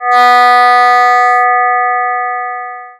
The difference here is that one totally omits the fundamental frequency of 245.88 Hertz (which is pretty close to the B below middle C assuming A440 tuning); odd harmonics are represented though both include the second harmonic at 491.76 Hertz.